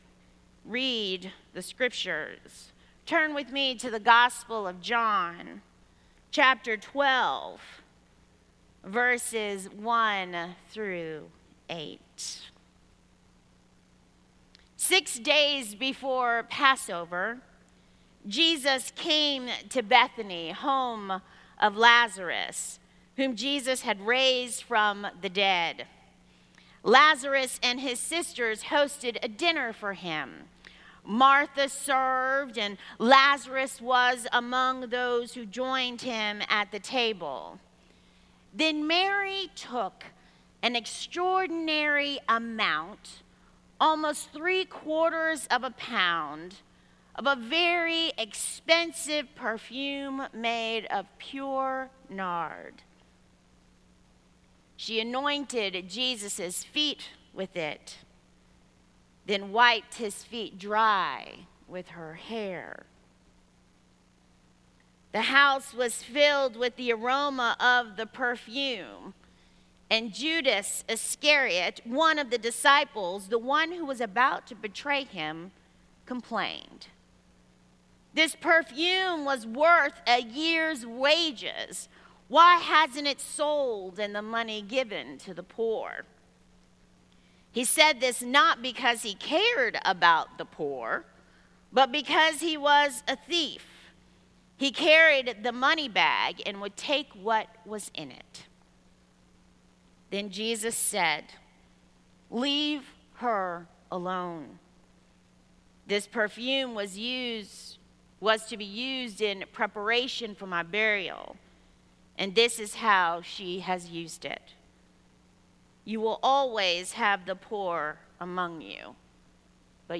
Sermon Archives - Cokesbury Church